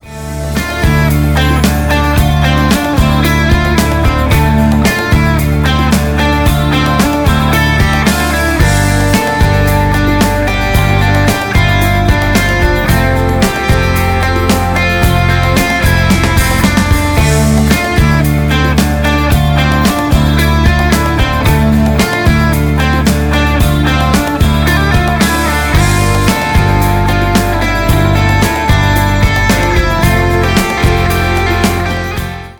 Рок Металл
без слов